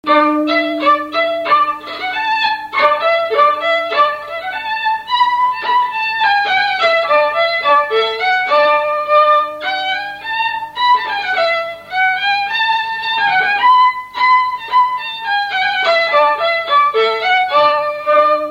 Berline
Résumé instrumental
Pièce musicale inédite